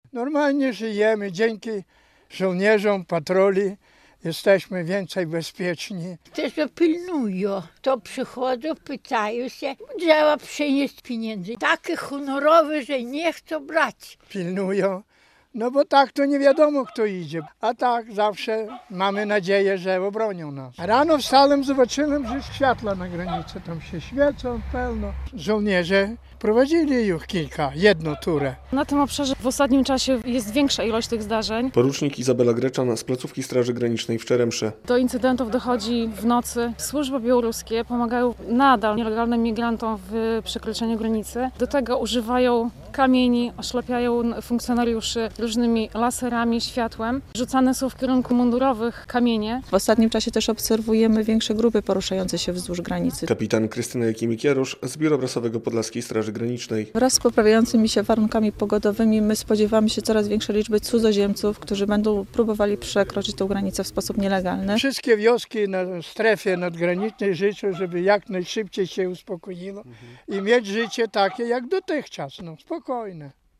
Mieszkańcy przygranicznej Bobrówki czują się bezpieczniej dzięki obecności żołnierzy - relacja